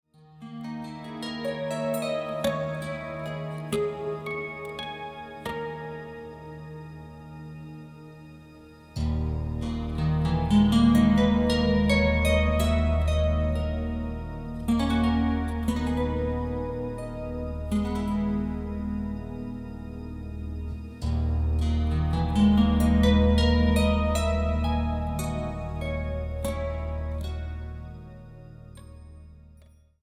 acoustic, modified pedal harps
embracing the violoncello